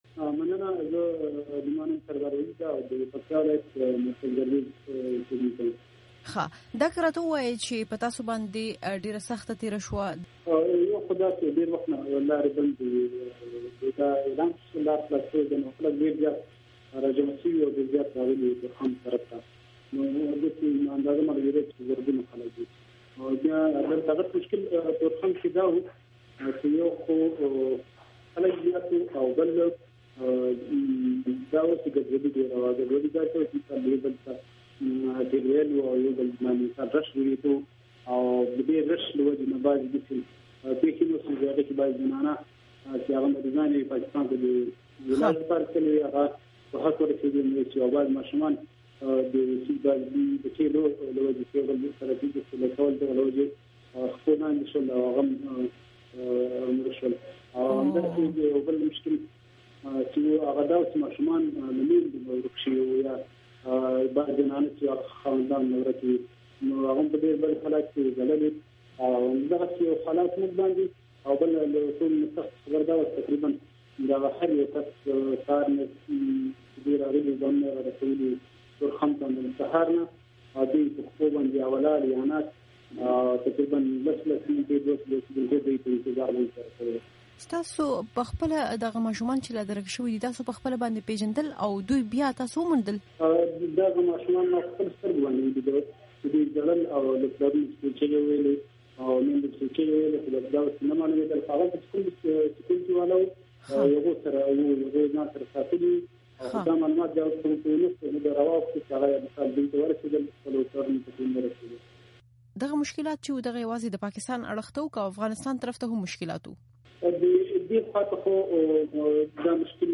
د تورخم د یو عیني شاهد سره مرکه